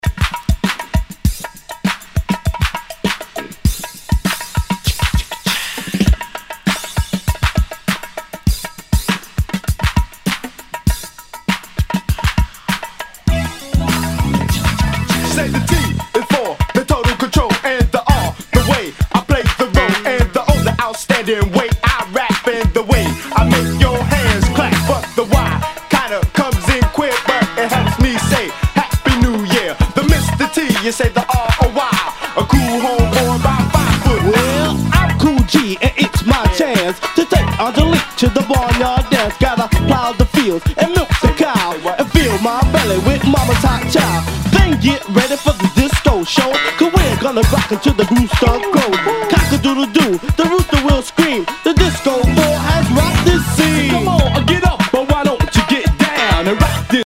HIPHOP/R&B
全体にチリノイズが入ります